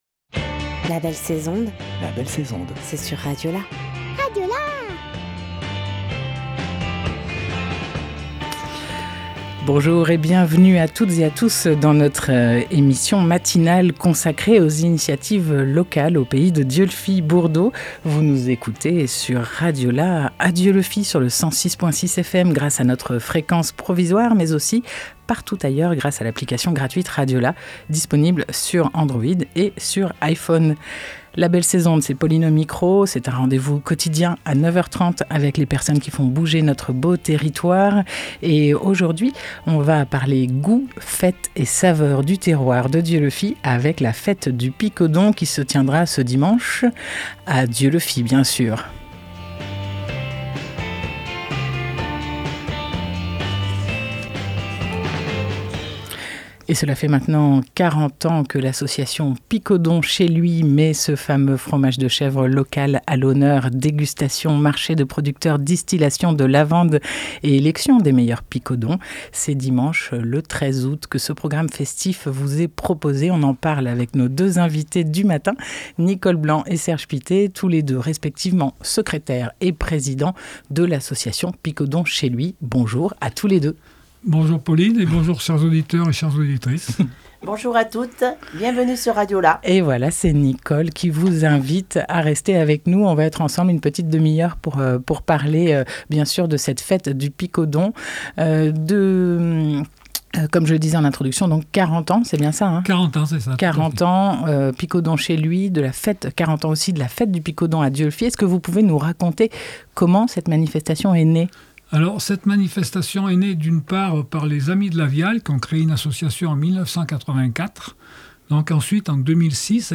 8 août 2023 11:05 | Interview, la belle sais'onde